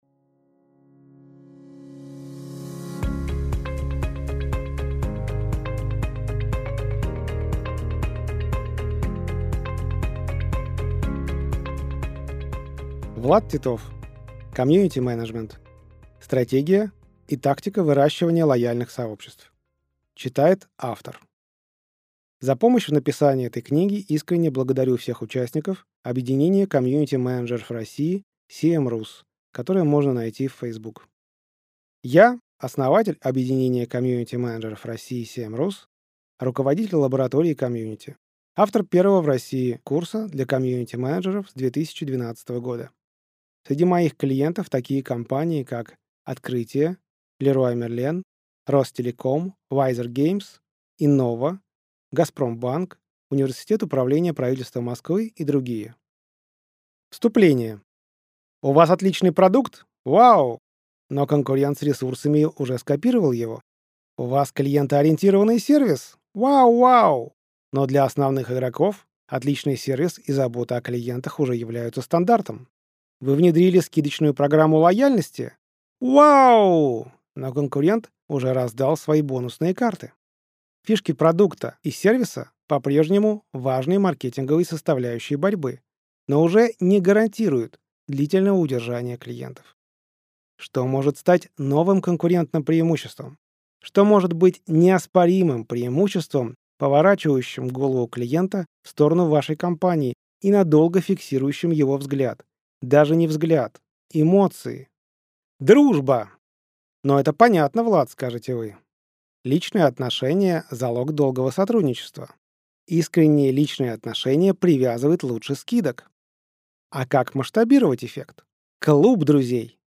Аудиокнига Комьюнити-менеджмент. Стратегия и практика выращивания лояльных сообществ | Библиотека аудиокниг